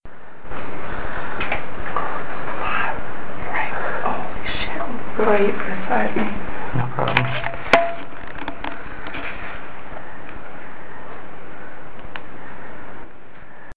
Sunbury Business EVP
Although the voice sound much like the one above, the message is not so clear.